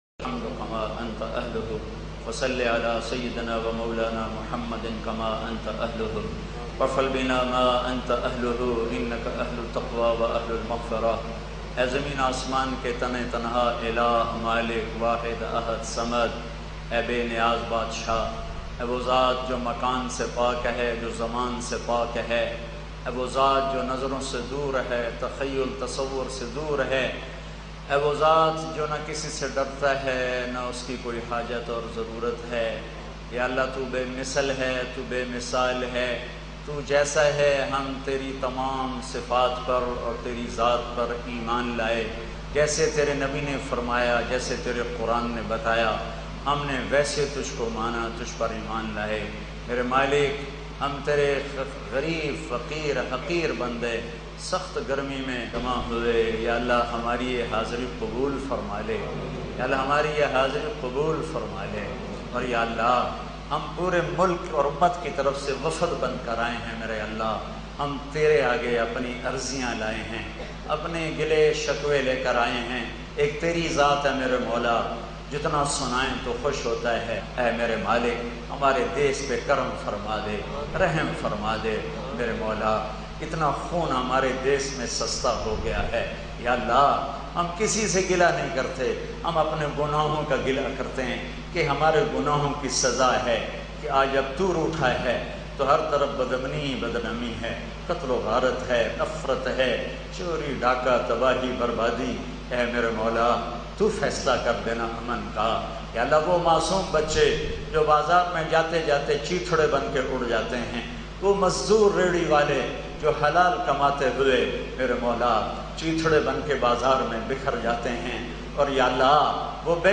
Laila ul Qadr Prayer Maulana Tariq Jameel lattest bayan
Laila-ul-Qadr-Prayer-by-Molana-Tariq-Jameel.mp3